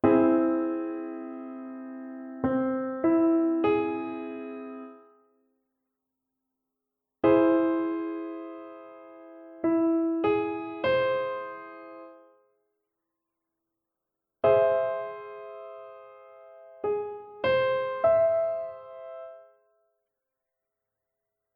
Bliebe als letzte Möglichkeit der Dreiklänge die Variante mit zwei großen Terzen (rechts):
Dadurch entsteht eine übermäßige Quinte als Rahmenintervall.
DreiklangAugGrundstellung.mp3